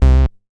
MoogResoG 007.WAV